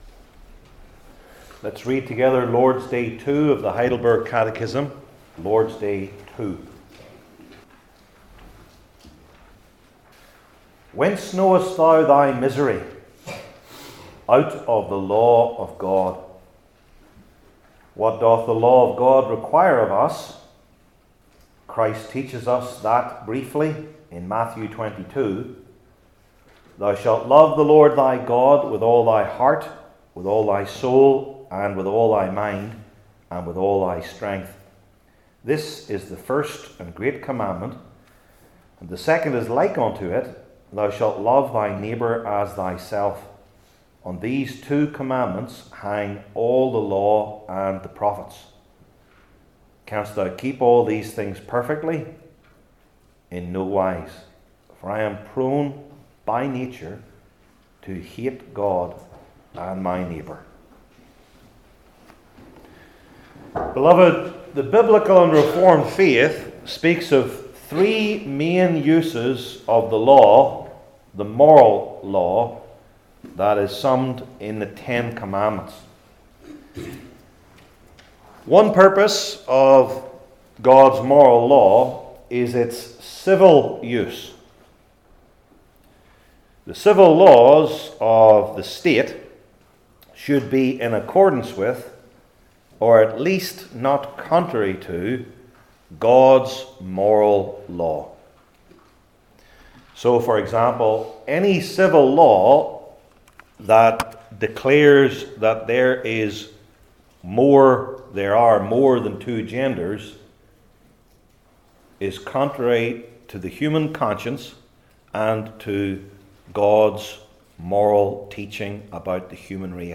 Heidelberg Catechism Sermons I. Through the Calling to Love God II.